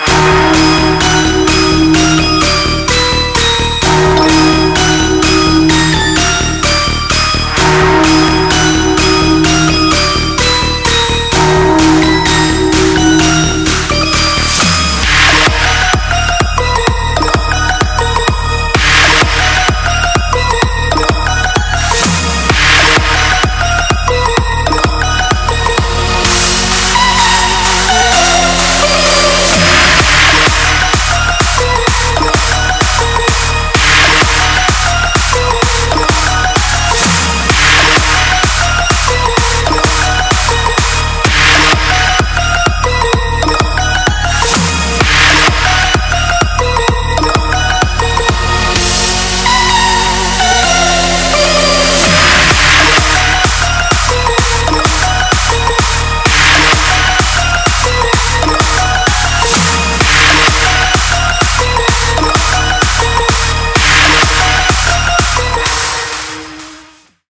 • Качество: 320, Stereo
громкие
без слов
club
колокольчики
рингтон с колокольчиками